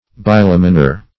Search Result for " bilaminar" : The Collaborative International Dictionary of English v.0.48: Bilaminar \Bi*lam"i*nar\, Bilaminate \Bi*lam"i*nate\, a. [Pref. bi- + laminar, laminate.]